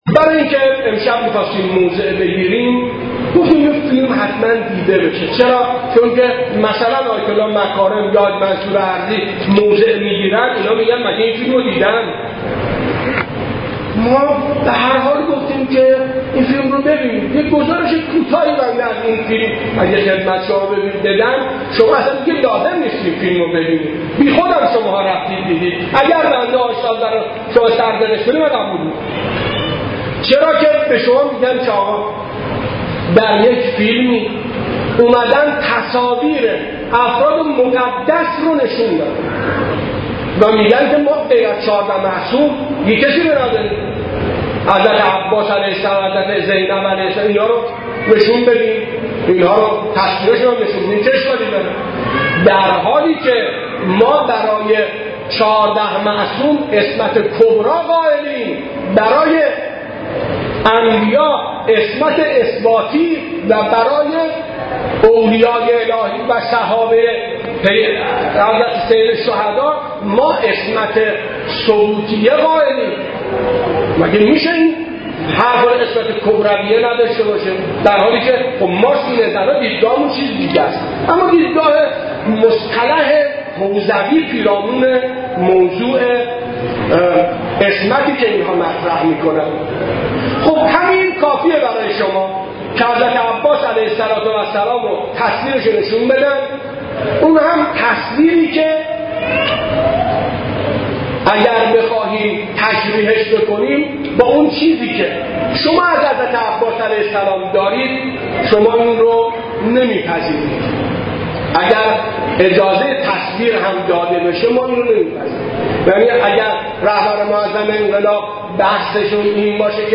تجمع اعتراضی هیئات مذهبی و مداحان، یک شنبه شب در مسجد ارگ برگزار شد.